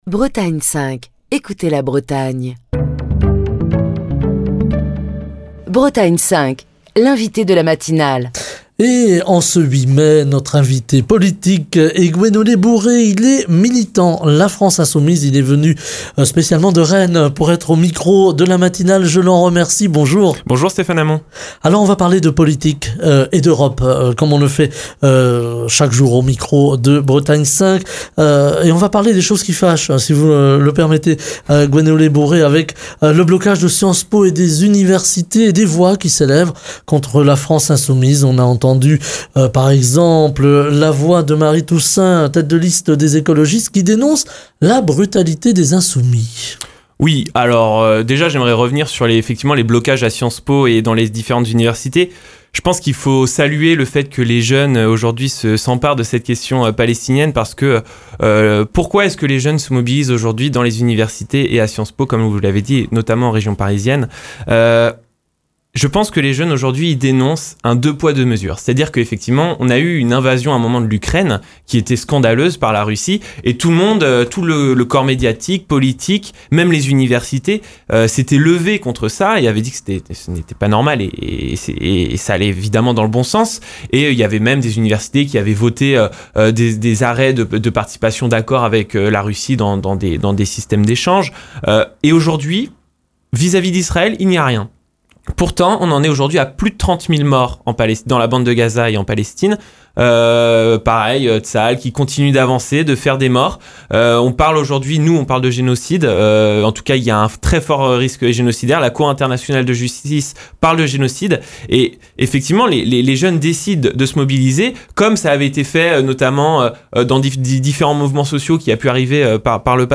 est l'invité politique de la matinale de Bretagne 5